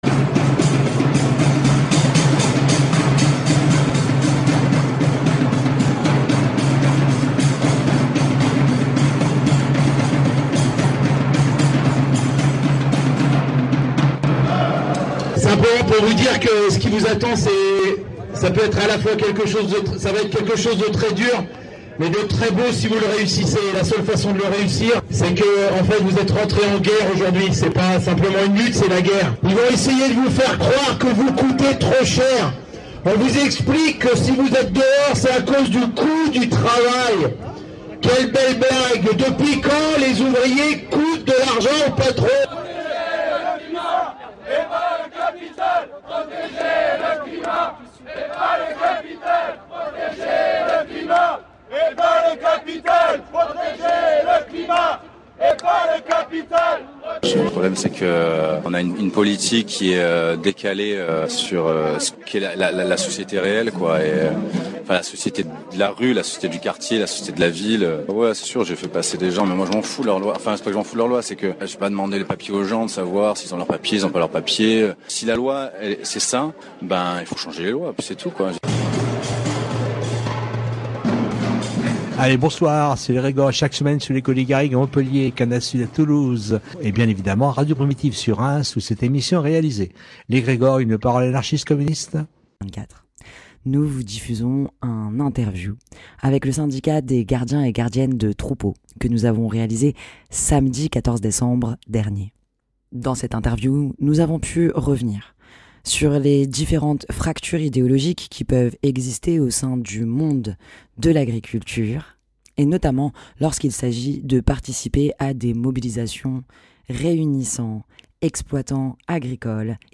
Dans cette émission, nous vous diffusons une interview réalisée avec trois membres syndicat des gardiens et gardiennes de troupeaux autour de la question du MERCOSUR et des mobilisations que ce traité a pu entrainer. Une occasion pour nous de revenir plus globalement sur les lignes de fractures idéologiques qui existe au sein du monde agro-alimentaire. classé dans : société Derniers podcasts Découvrez le Conservatoire à rayonnement régional de Reims autrement !